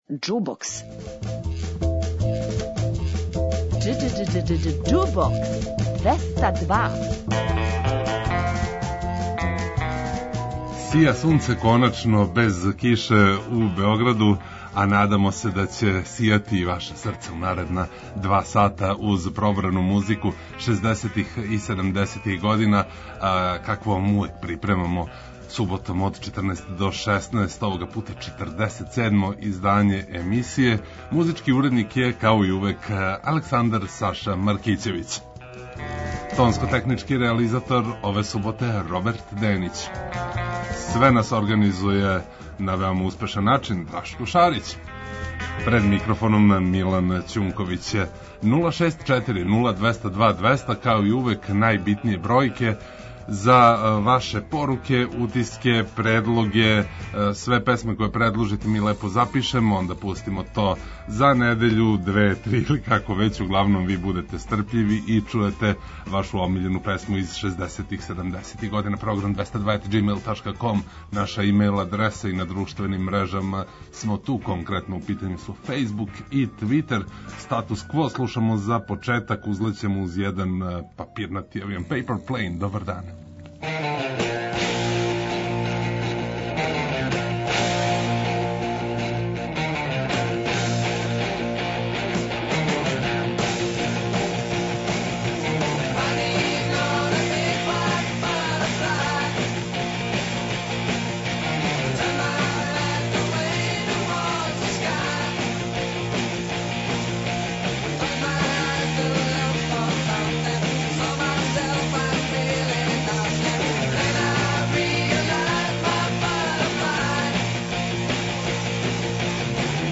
Београд 202 Уживајте у пажљиво одабраној старој, страној и домаћој музици.